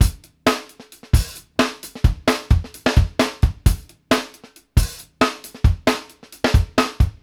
FUNK+OPN H-R.wav